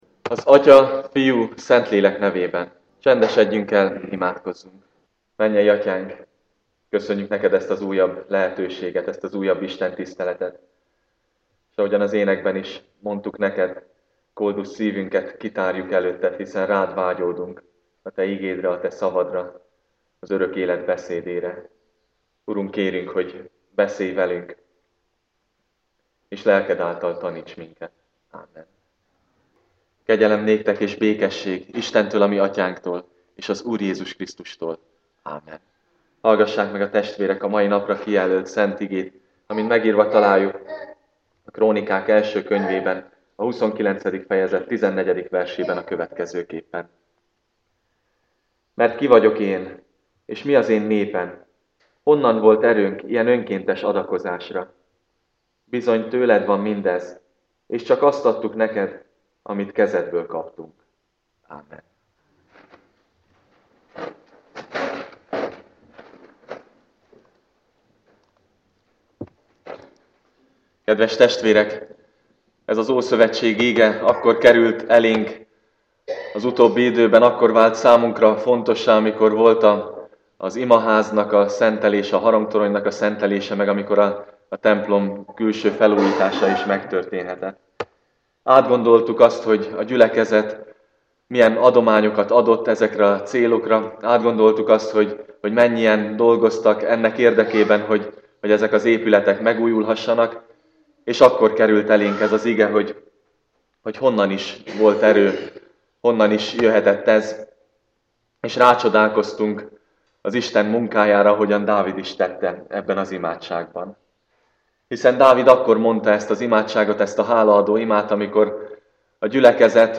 igehirdetése